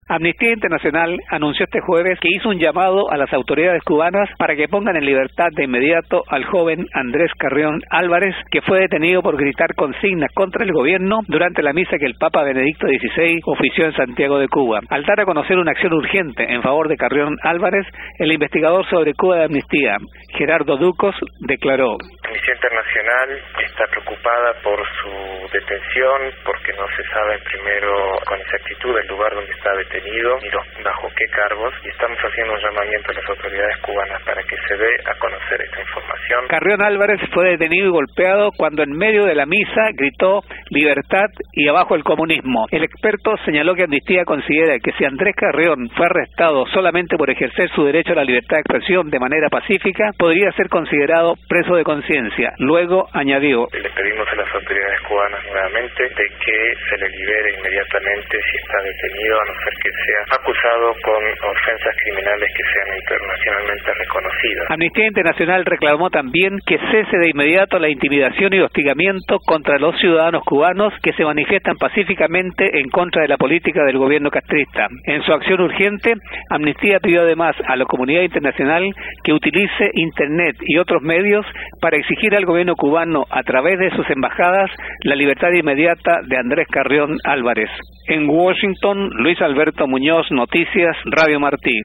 Declaración